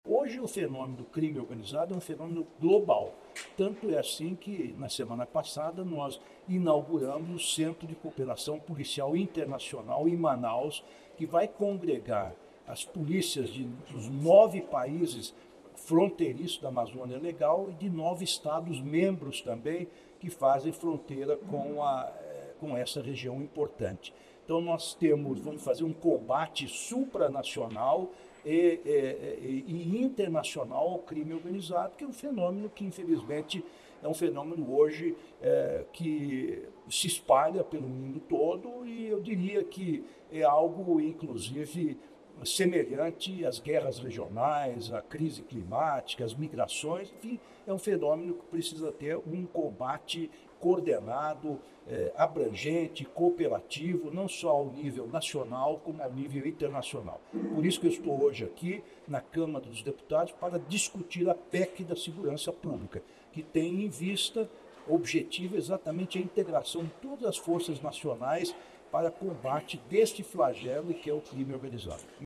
Ministro Lewandowski fala sobre a PEC da Segurança Pública em comissão na Câmara dos Deputados — Ministério da Justiça e Segurança Pública